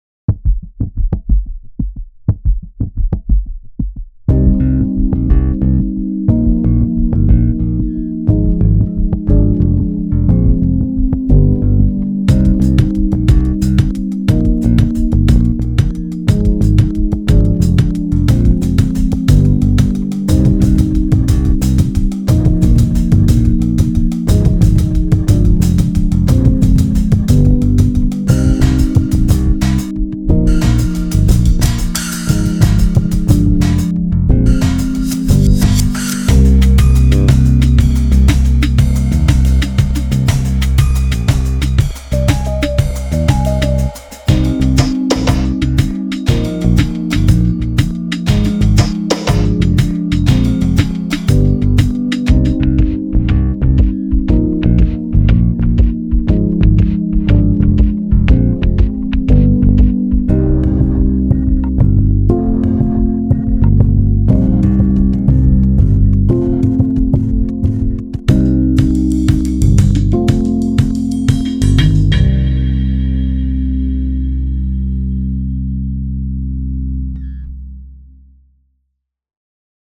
Genre:Pop
ドラムも不要、無駄もなし。
あるのは金属の質感、動き、そして緻密な録音だけです。
ここは高い天井と特徴的なレンガの反響を持つ改装された納屋で、自然な空間感と奥行きを音にもたらします。
セッションでは精密な14マイク構成が使用されました。